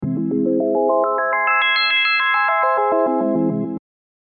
Download Spell sound effect for free.
Spell